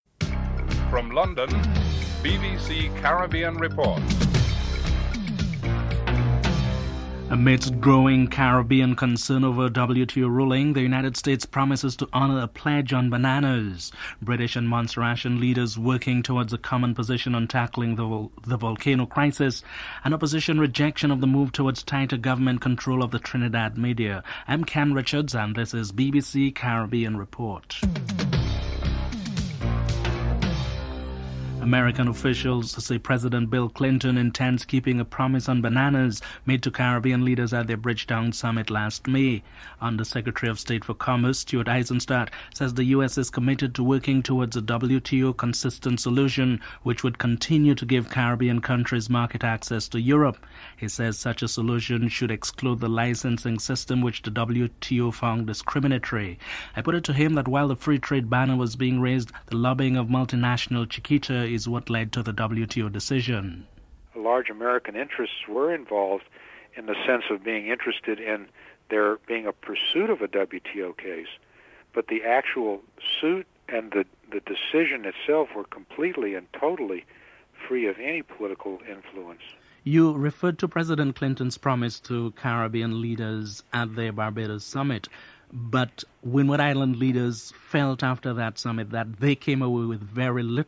1. Headlines (00:00-00:30)
4. British and Montserratians leaders are working toward a common position on tackling the volcano crisis. Chief Minister of Montserrat David Brandt is interviewed (07:09-09:11)
Jamaican Foreign Minister Seymour Mullings is interivewed (13:58-15:24)